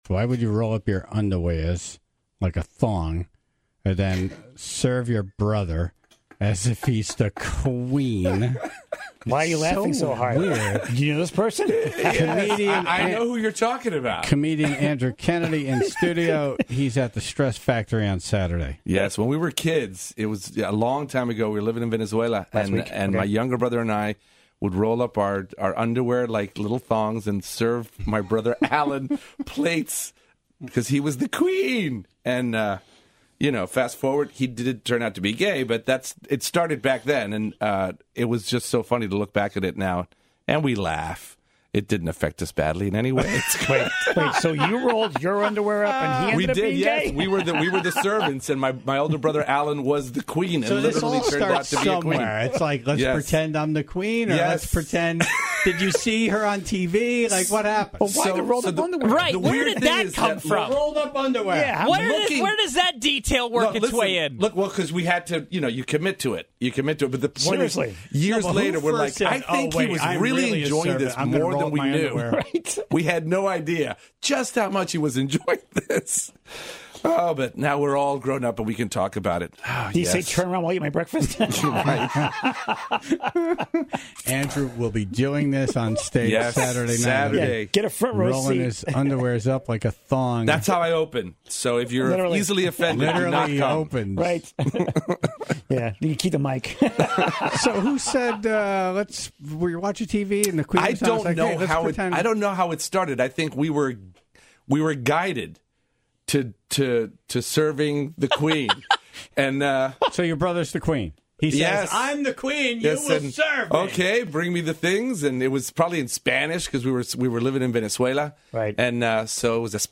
The Tribe also called in to share their weirdo childhood behaviors.